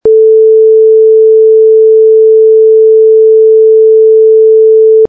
sine440hz.mp3